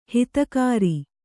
♪ hitakāri